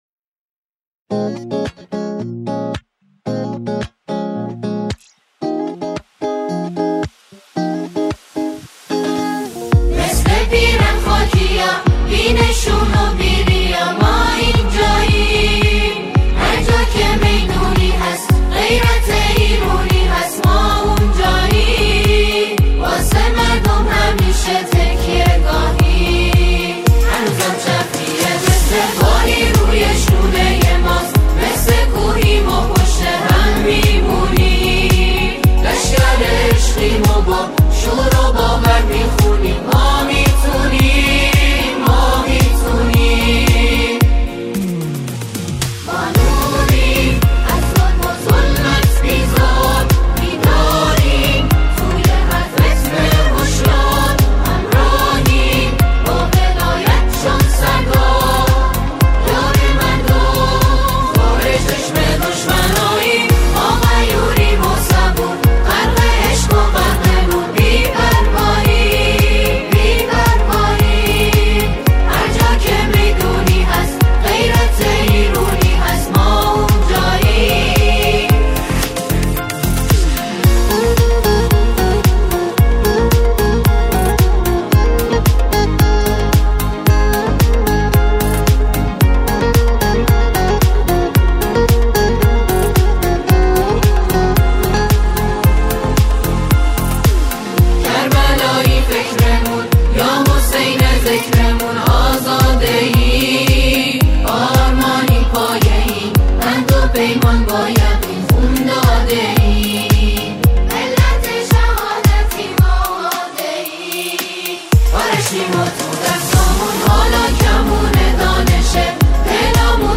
سرودهای بسیج